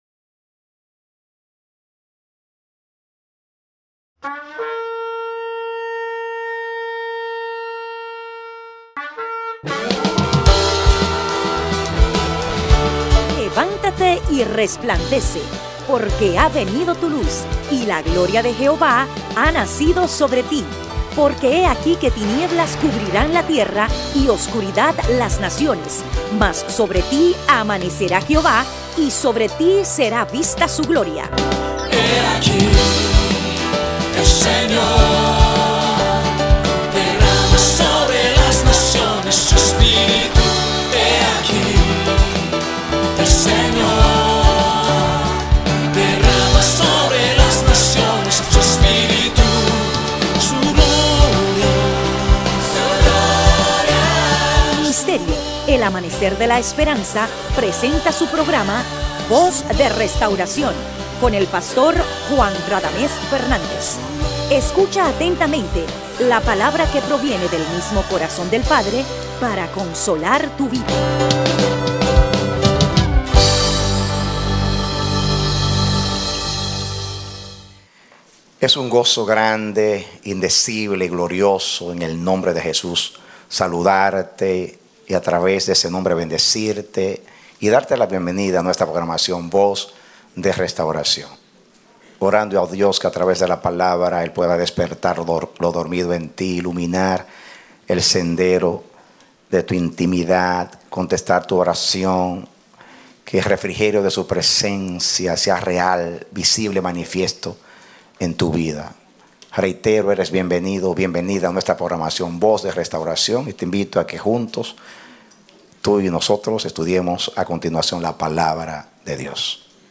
Predicado Jueves 21 de Enero, 2016